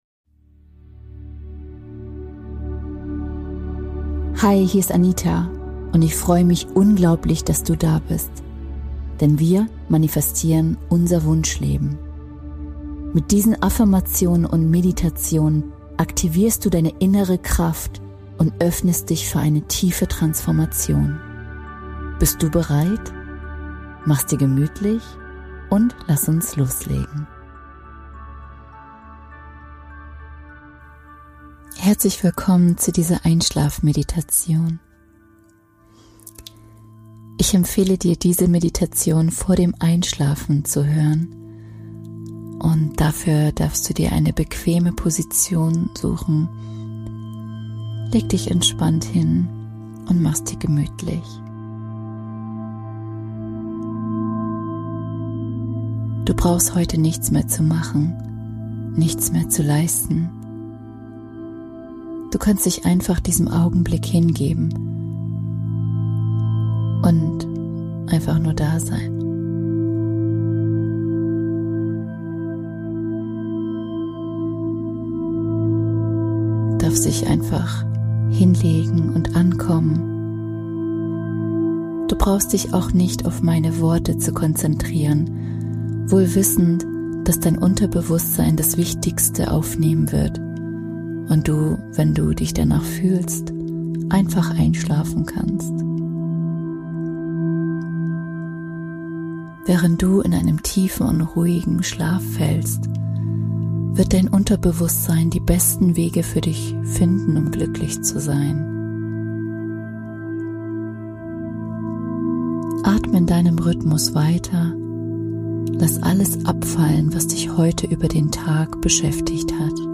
Entdecke in dieser Folge eine sanfte Einschlafmeditation, die dich liebevoll durch den Abend begleitet und dir hilft, den Tag in Frieden abzuschließen.